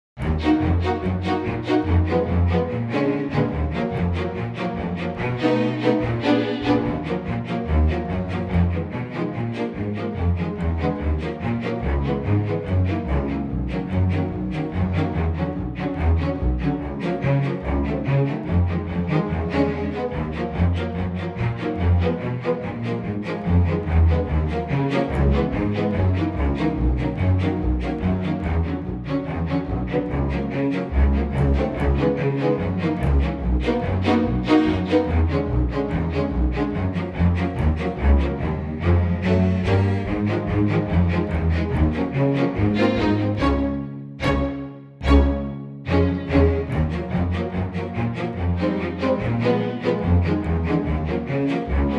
Unique Backing Tracks
key - Gb - vocal range - Bb to Db
Stunning and intimate strings only arrangement
very bright in tempo but it works so well.